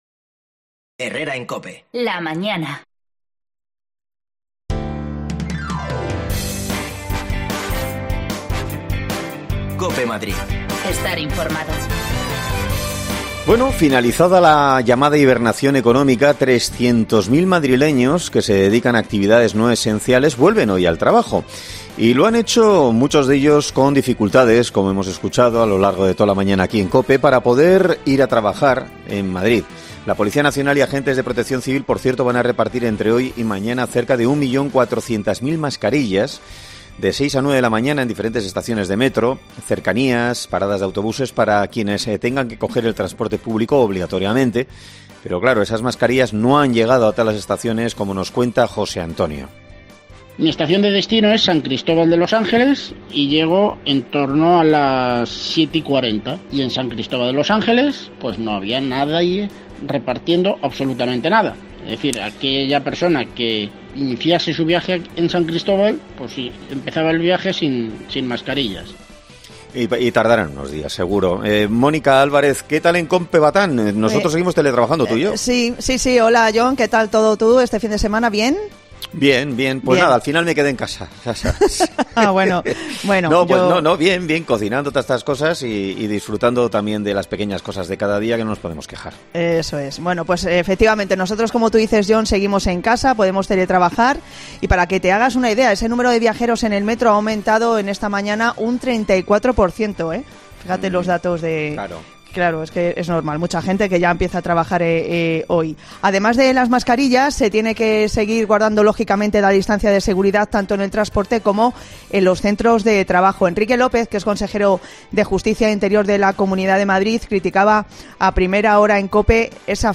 AUDIO: Te actualizamos las cifras del coronavirus en Madrid y escuchamos a madriileños después de este mes de confinamiento